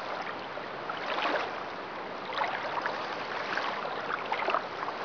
AguaPantano.wav